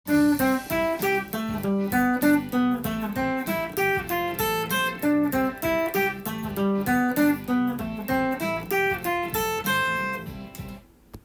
コード進行は、C/G/Am/F
コードトーン練習TAB譜
譜面通り弾いてみました